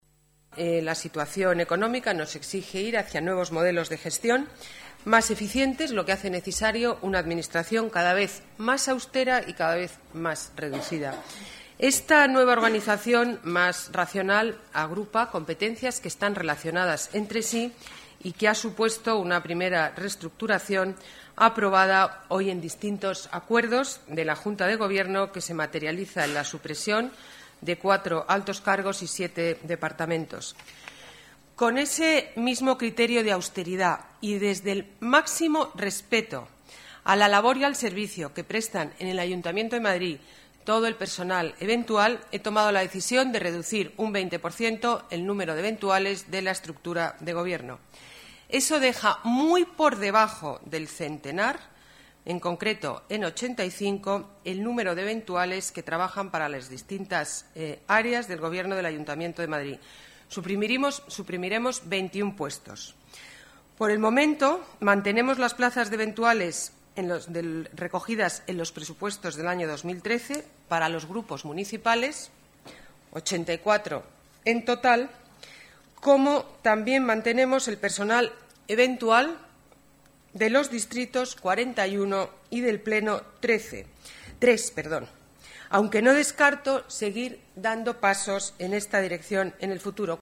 La alcaldesa, Ana Botella, informa de la reestructuración aprobada hoy en Junta de Gobierno